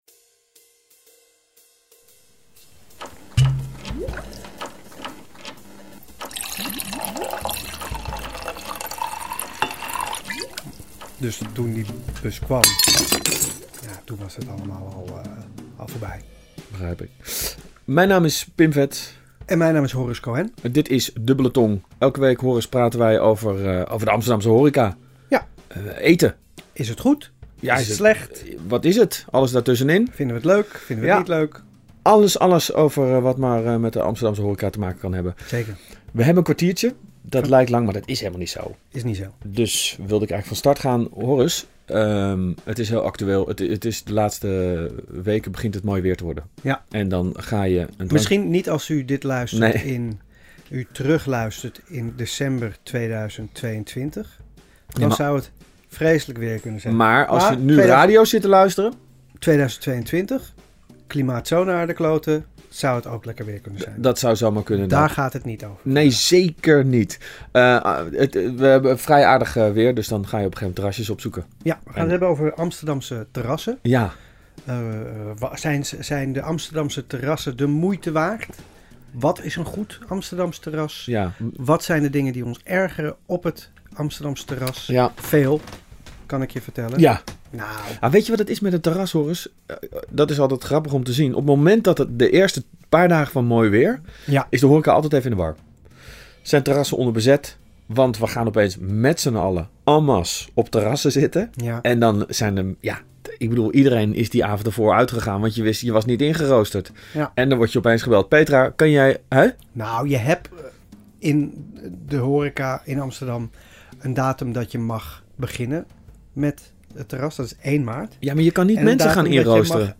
Dubbele Tong in Scheltema Live: dat is een stief kwartiertje slap Mokums geouwehoer over eten en drinken in de mooiste stad van de wereld.